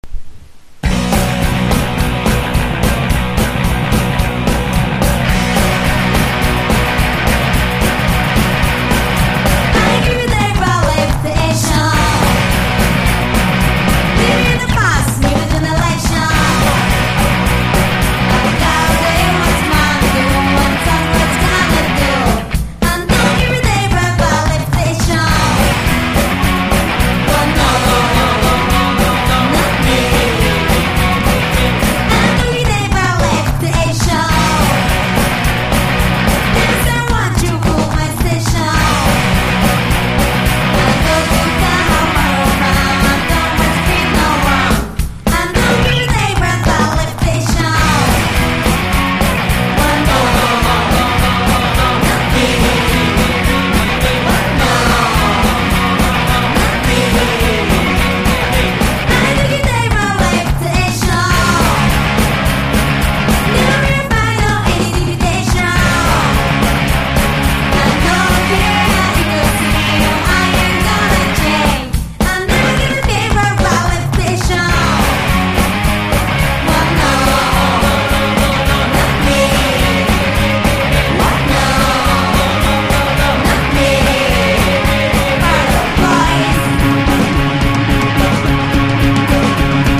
GARAGE ROCK (90-20’s)